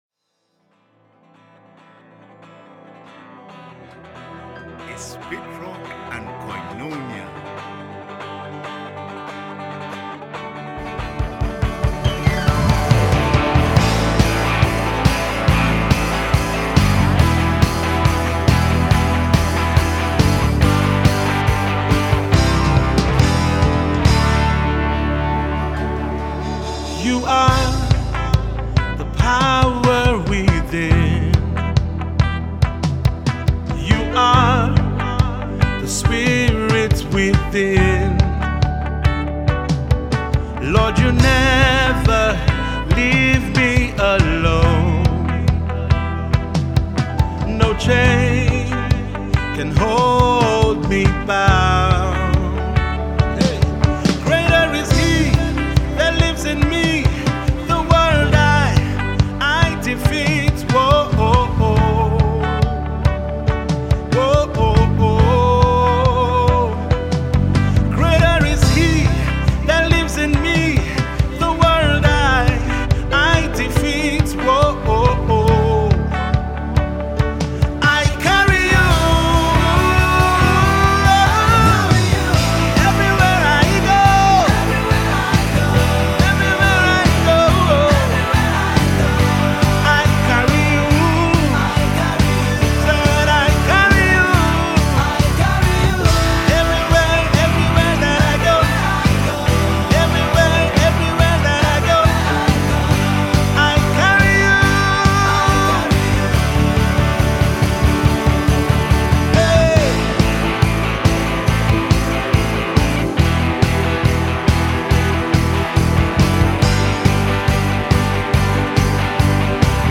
a dynamic Worship team